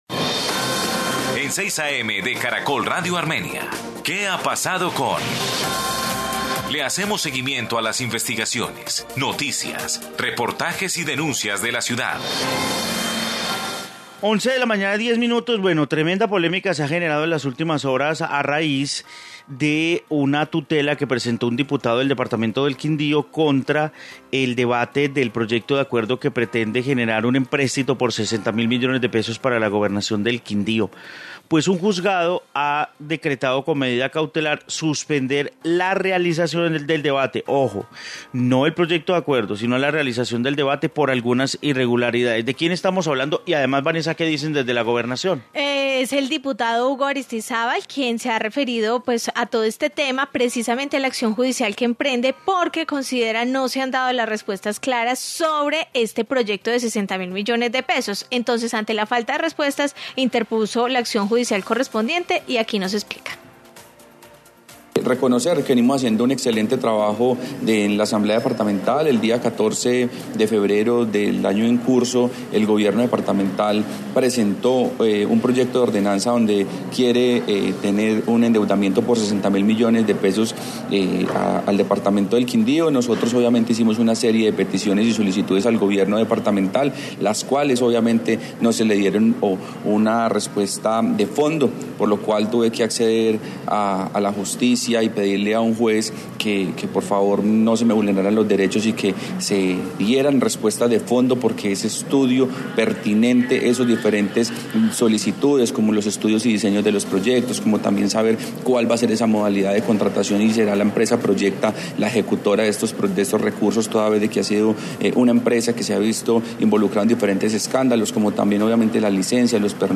Informe proyecto de endeudamiento